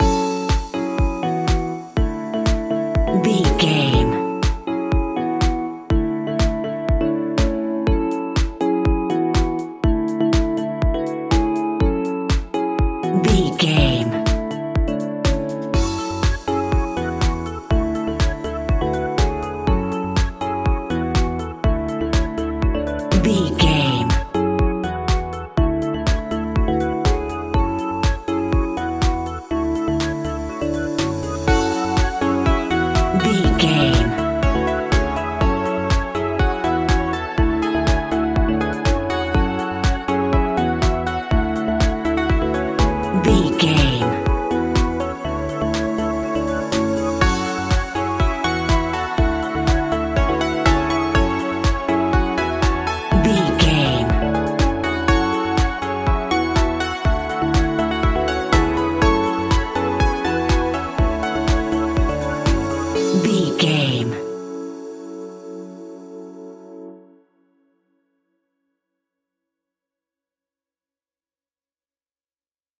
Aeolian/Minor
F#
groovy
happy
piano
drums
drum machine
synthesiser
house
electro
electro house
funky house
synth leads
synth bass